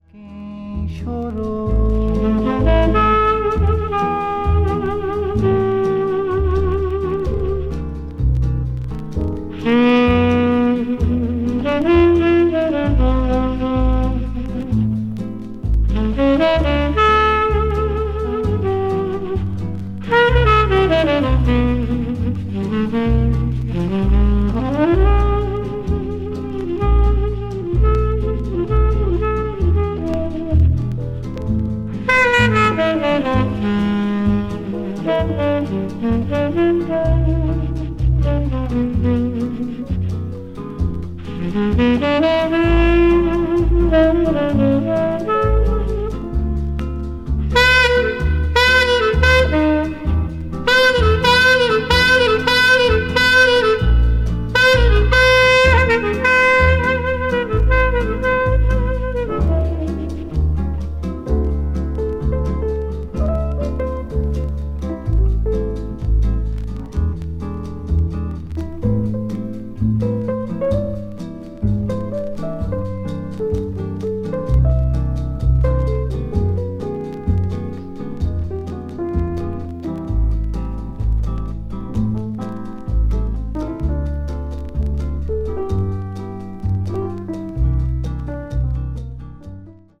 media : EX+/EX-(わずかにチリノイズが入る箇所あり,B1:ごく薄い擦れあり)